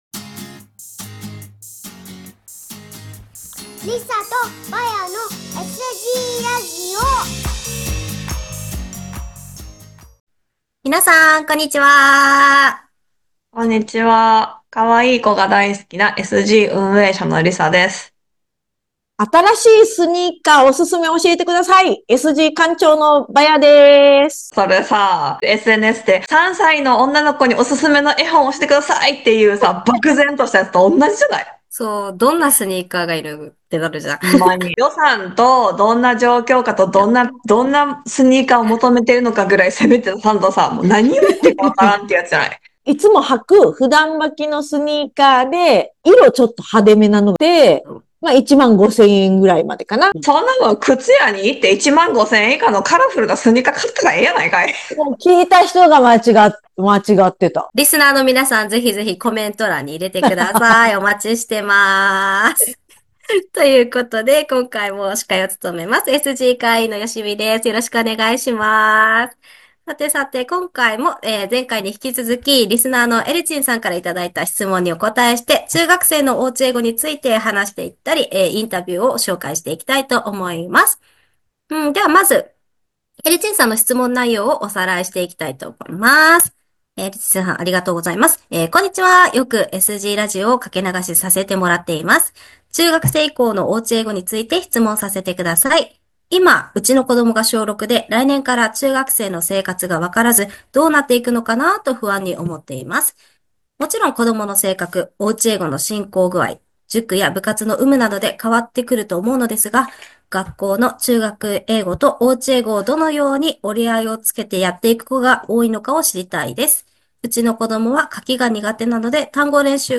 Vol.71「中学生の親の本音！インタビュー2/2」